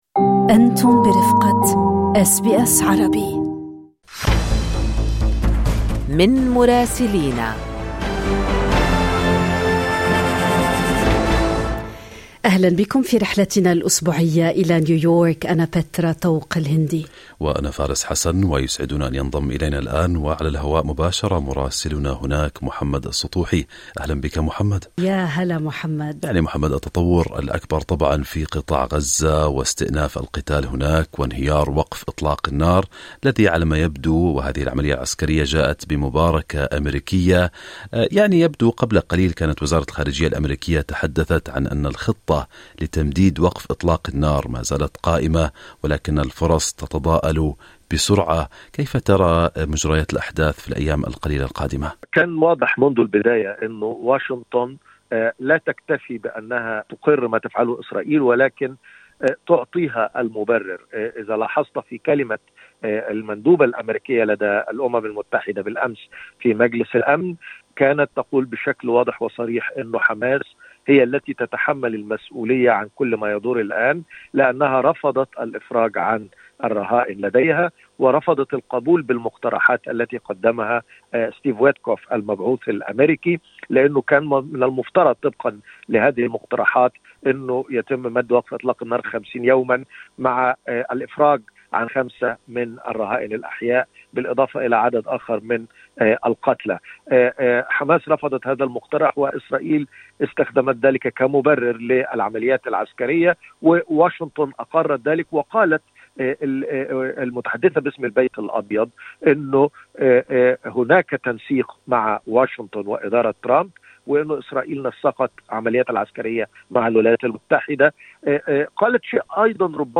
من مراسلينا: أخبار الولايات المتحدة الأمريكية في أسبوع 20/3/2025